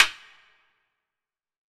CONGA 35.wav